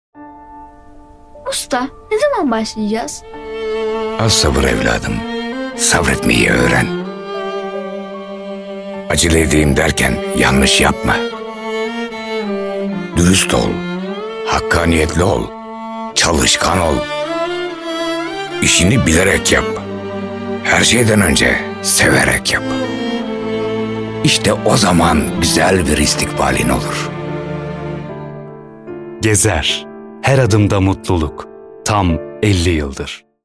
Features: Voice Over, Actor, Promotion, Documentary, Advertising, Drama, Corporate, Podcast, Comedy, Video Game, Audiobook.